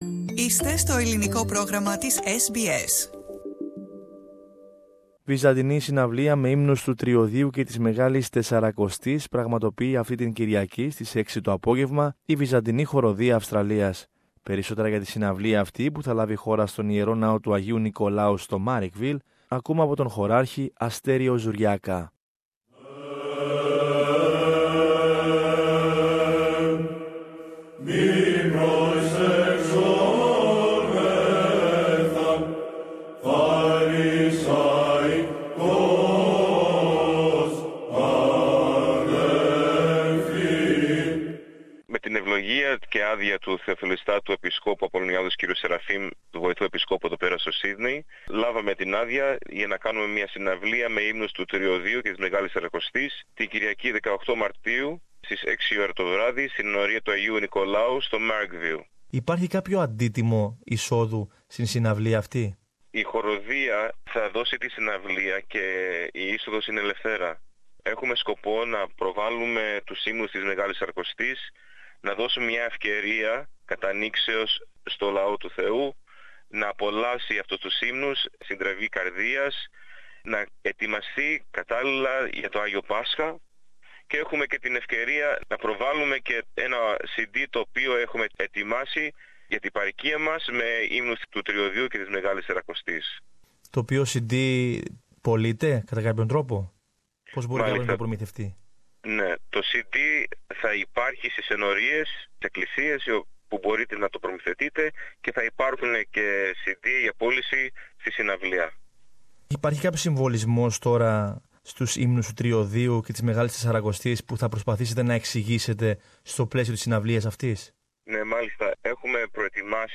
συνομιλία